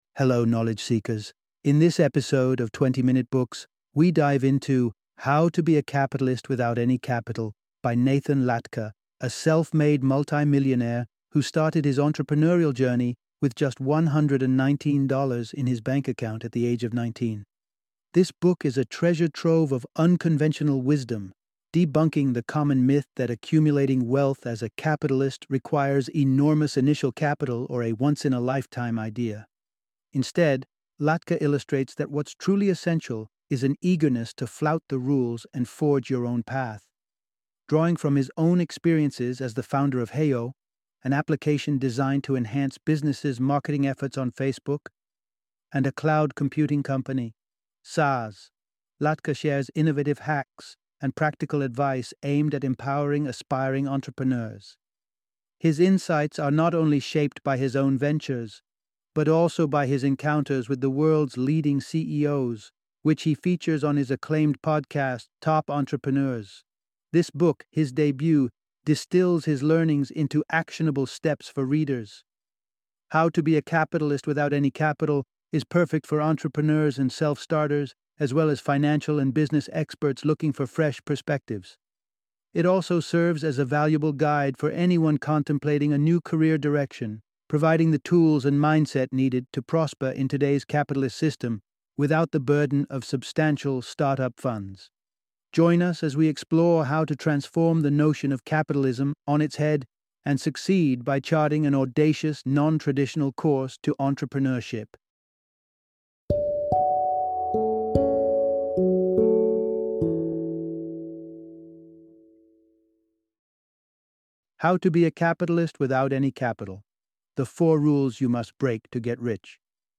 How to Be a Capitalist Without Any Capital - Audiobook Summary